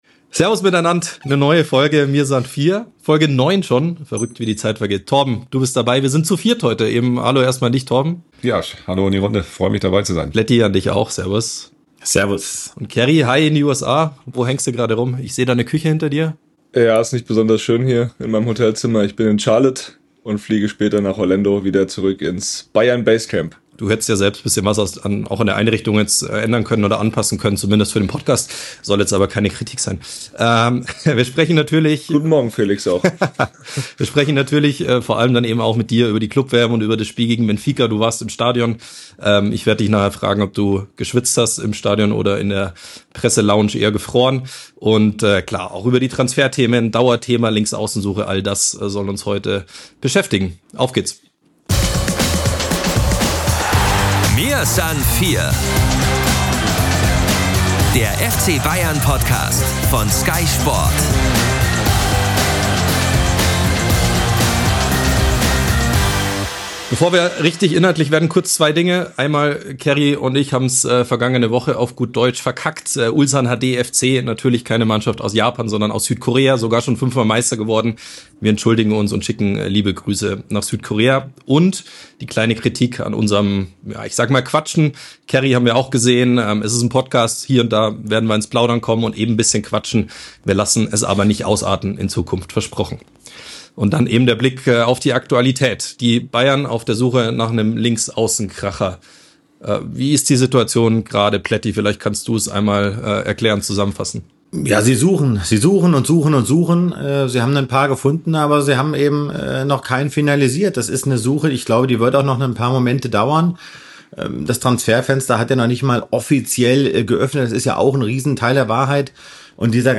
Beschreibung vor 8 Monaten Am Mittwochnachmittag wurde die neunte Folge Mia san vier aufgenommen - mit allen vier Bayern-Reportern.